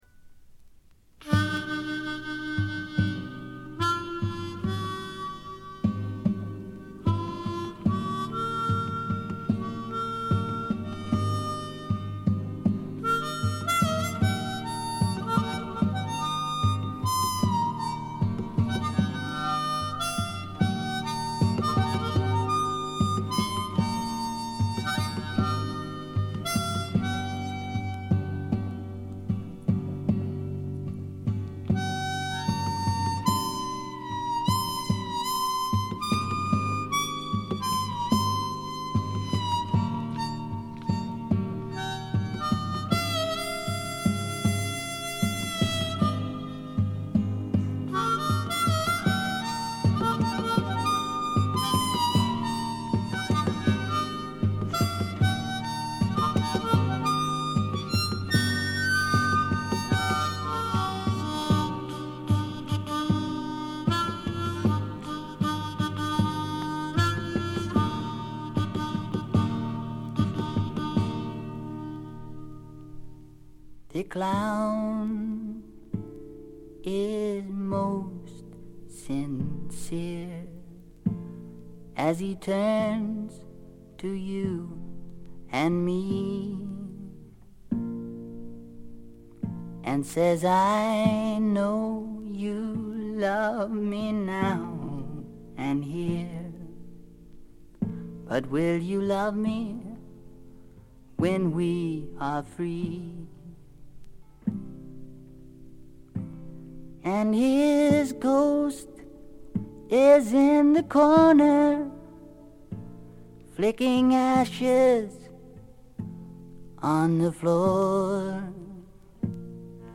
スワンプ路線とフォーキー路線が半々でどちらも素晴らしい出来ばえです。
試聴曲は現品からの取り込み音源です。
Vocals, Acoustic Guitar